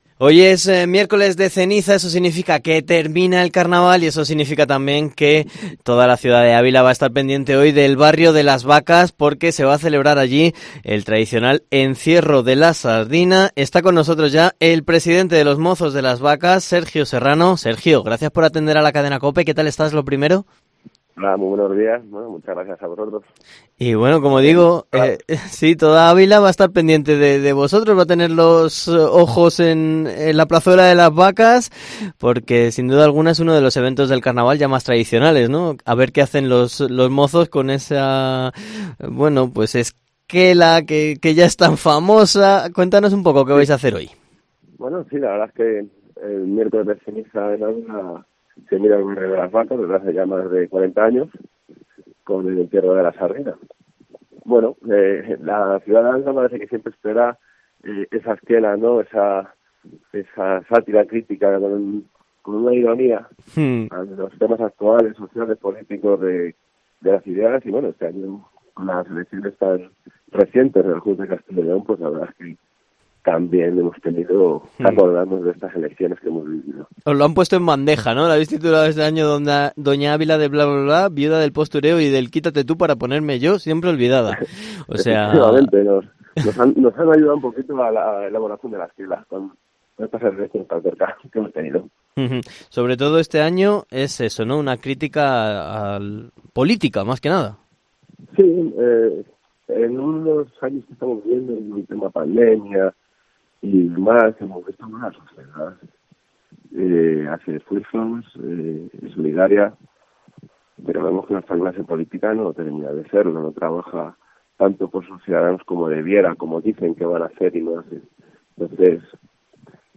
Entrevista-mozos-de-las-vacas-entierro-de-la-sardina de Ávila